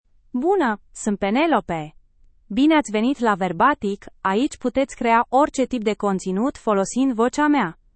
PenelopeFemale Romanian AI voice
Penelope is a female AI voice for Romanian (Romania).
Voice sample
Female
Penelope delivers clear pronunciation with authentic Romania Romanian intonation, making your content sound professionally produced.